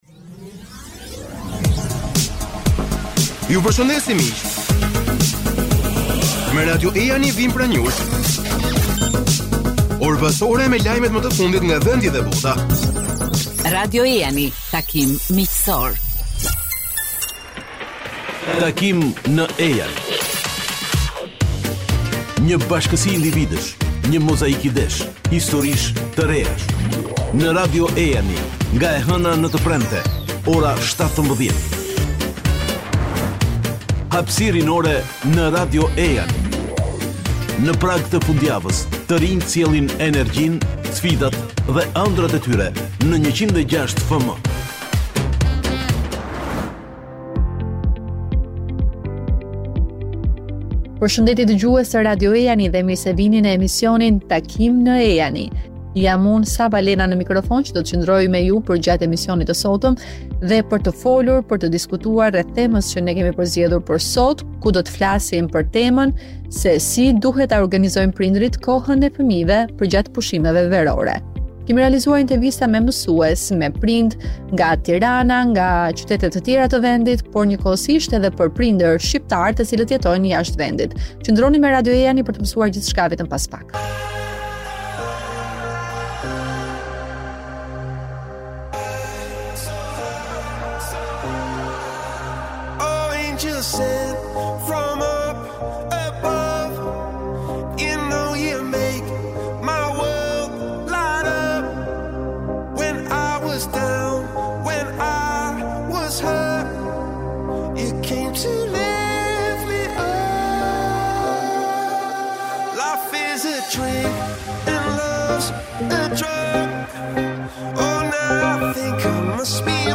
Në intervistë për Radio Ejani disa prindër japin opinionet e tyre për këtë temë.